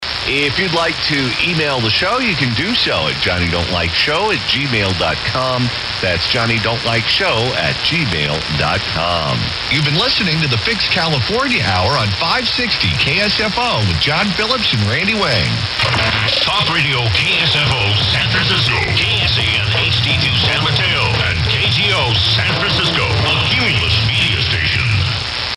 810 is still IDing as KGO. Here is the top of hour ID from today at 1200UTC.